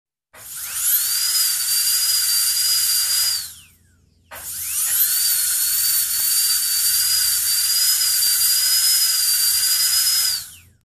На этой странице собраны разнообразные звуки из кабинета стоматолога: от жужжания бормашины до характерных щелчков инструментов.
Звук стоматологического сверла